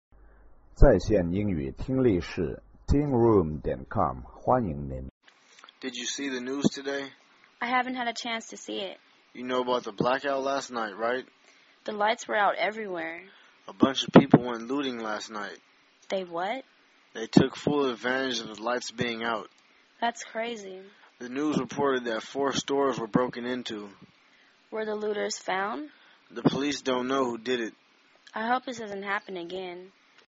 英语情景对话-Discussing a News Report on a Crime(2) 听力文件下载—在线英语听力室